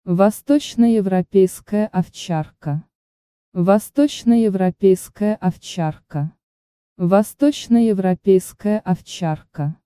EAST EUROPEAN SHEPHERD - Vostochnoevropeiskaya Ovcharka, Восточноевропейская овчарка